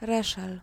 Reszel [ˈrɛʂɛl]
Pl-Reszel.ogg.mp3